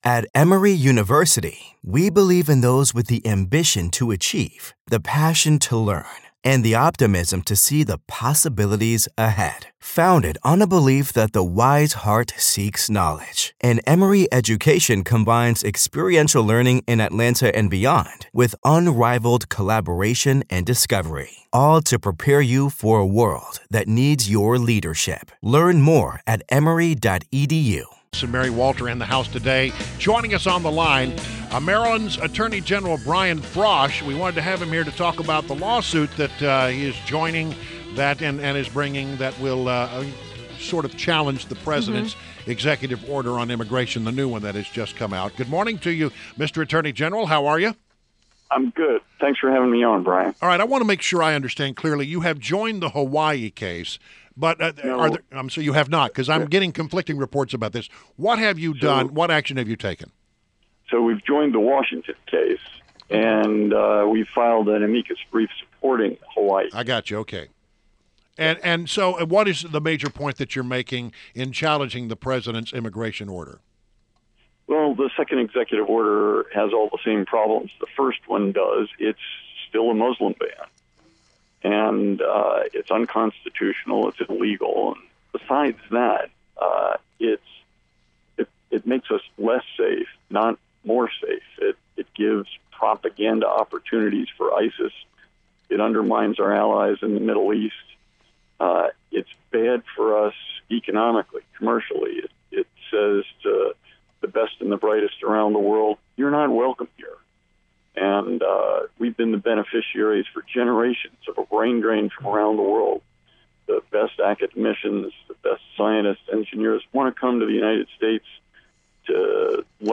INTERVIEW — MARYLAND ATTORNEY GENERAL BRIAN FROSH